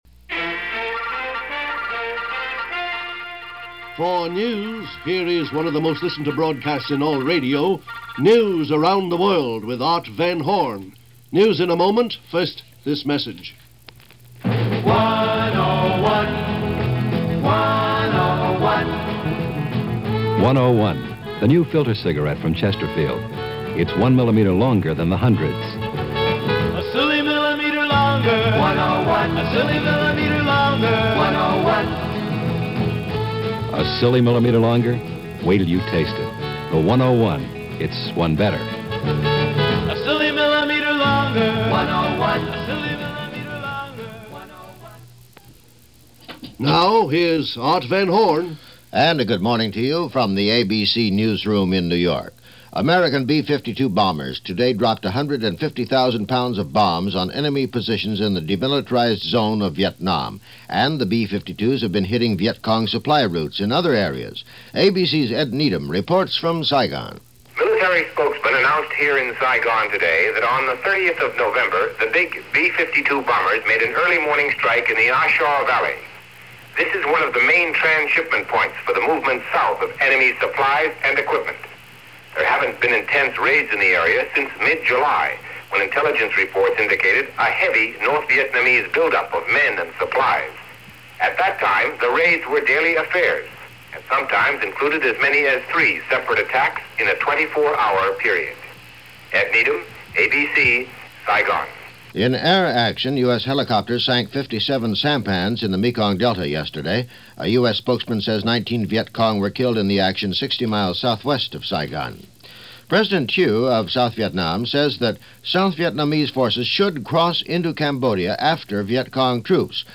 Vietnam: An Urgent Need For Peace - December 2, 1967 - News from ABC Radio's News Around The World - Vietnam War and other stories.